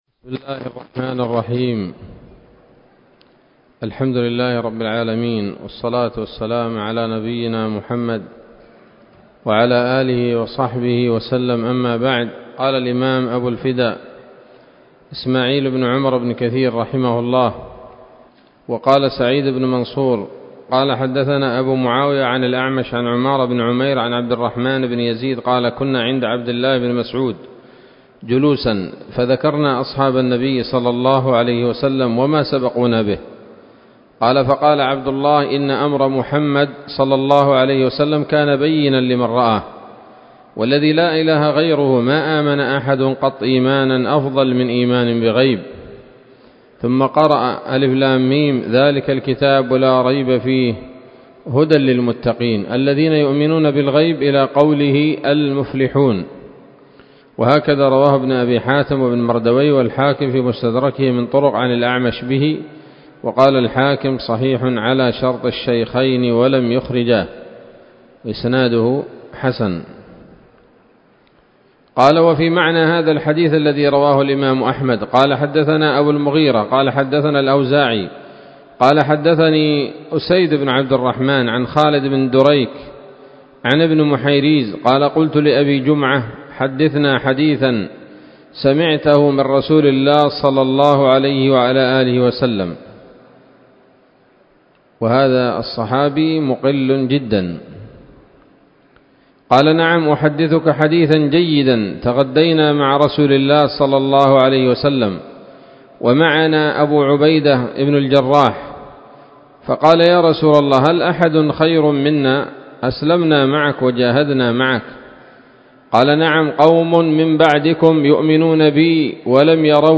الدرس العاشر من سورة البقرة من تفسير ابن كثير رحمه الله تعالى